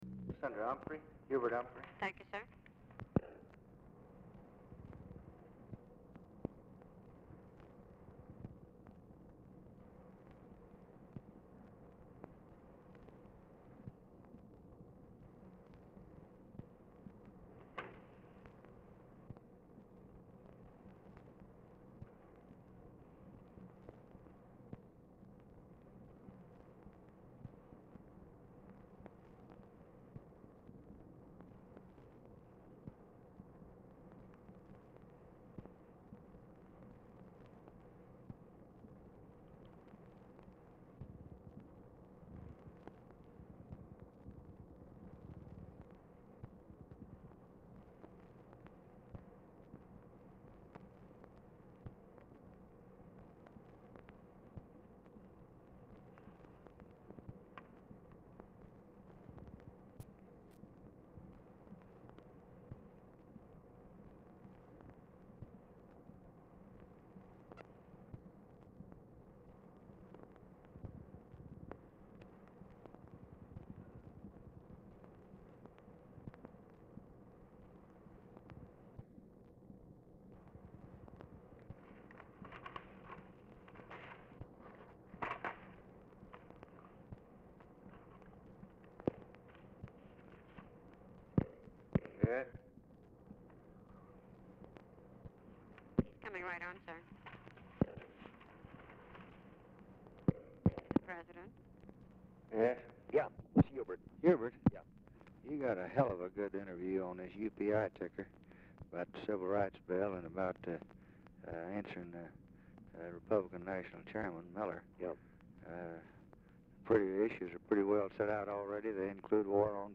Telephone conversation # 1601, sound recording, LBJ and HUBERT HUMPHREY, 1/28/1964, 6:15PM
Format Dictation belt
Location Of Speaker 1 Oval Office or unknown location